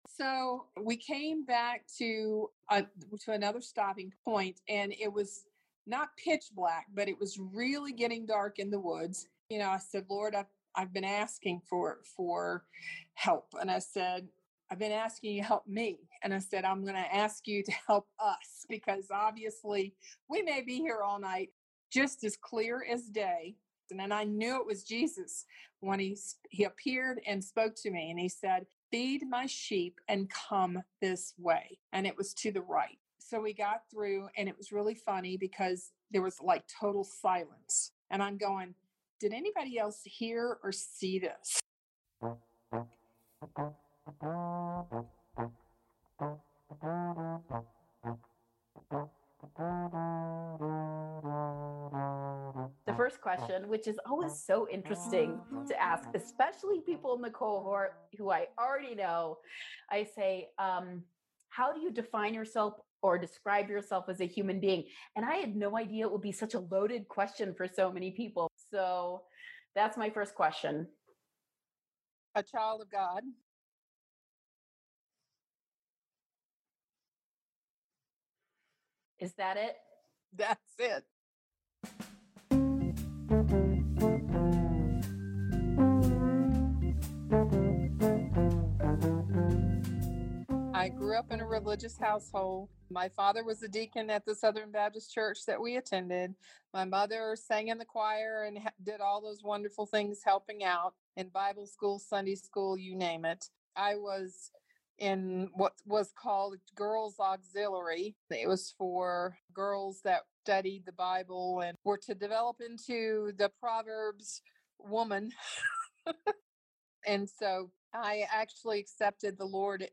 In this longer interview, listen in on how she found the way, not only for herself, but her friends as well.